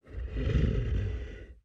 描述：熊咆哮，使用人声和声乐变换器模拟 WAV 16位44.1Khz
Tag: 生物 动物 吸气 呼气 恐怖 怒吼 可怕 怪物 呼吸 咆哮 呼吸